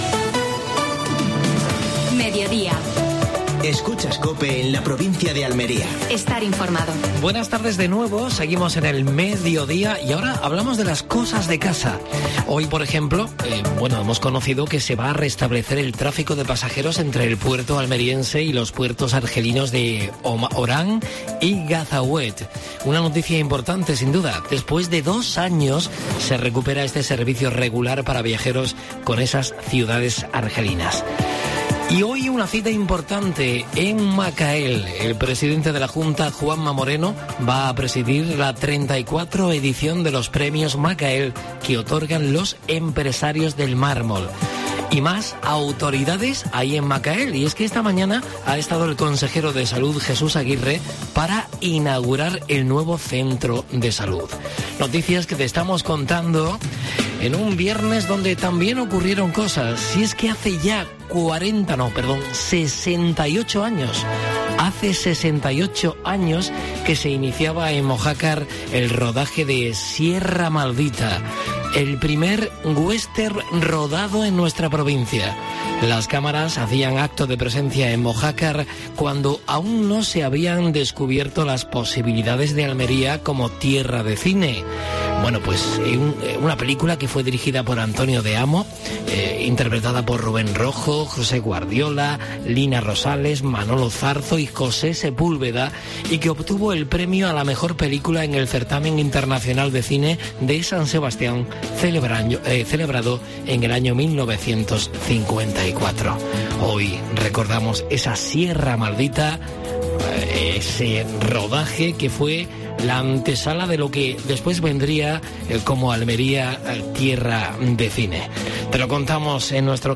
Actualidad en Almería. Entrevista a Ismael Torres (alcalde de Huércal de Almería). Última hora deportiva.